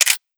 CameraSnapshot.wav